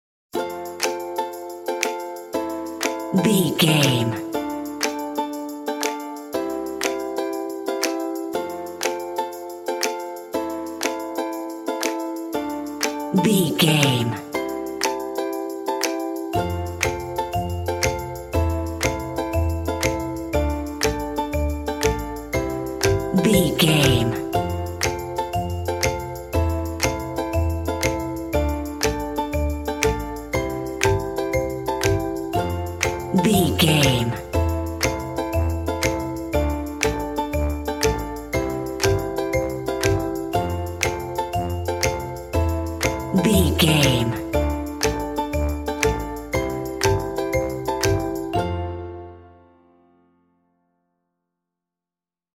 Ionian/Major
cheerful/happy
dreamy
bells
percussion